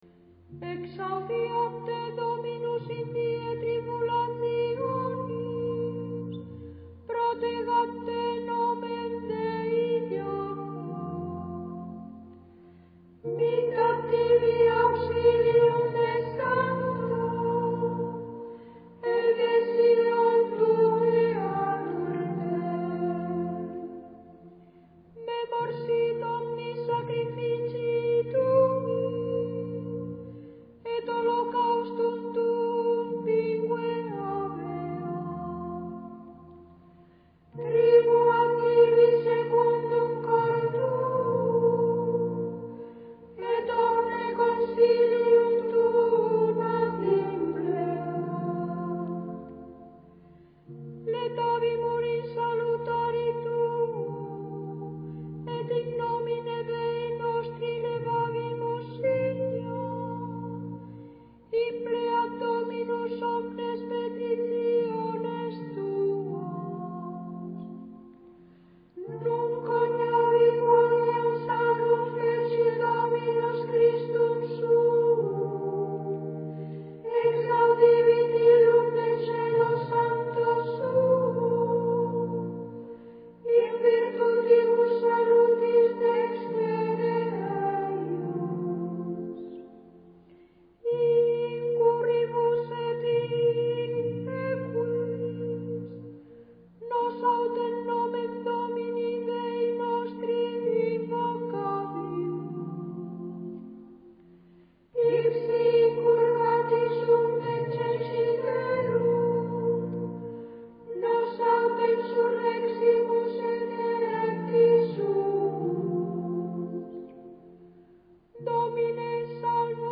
El mismo salmo, cantado: